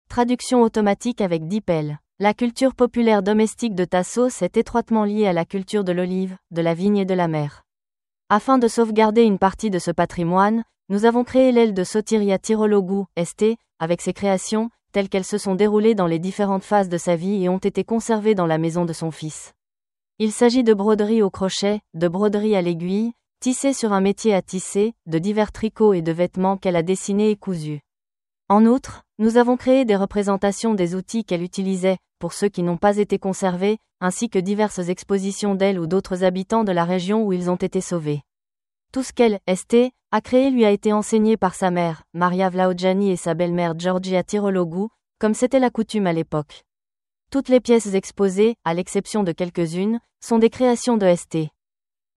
Visite guidée audio